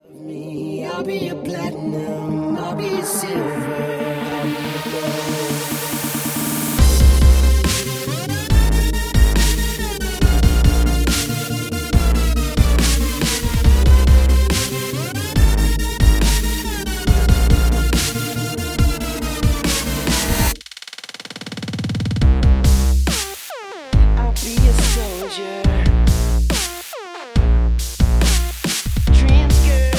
Backing track files: 2010s (1044)